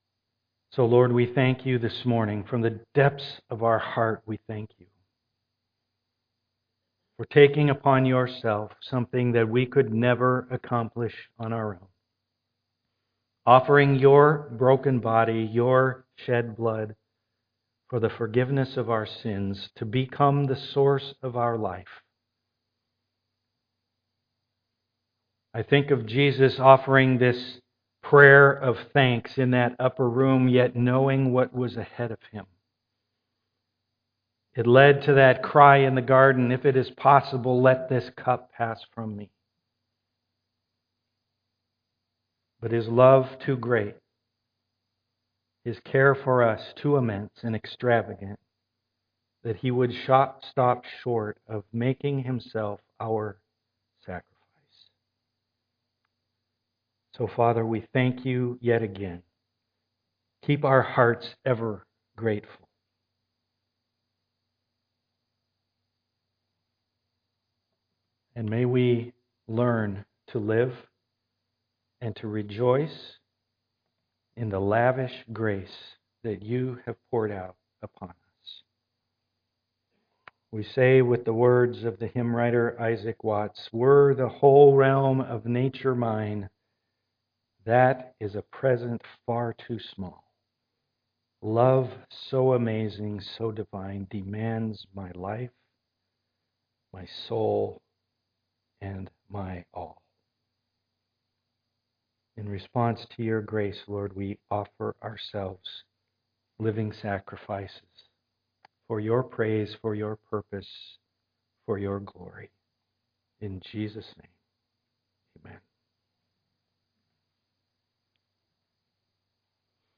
The message will be recorded live on Sunday morning and posted by early afternoon for our virtual service.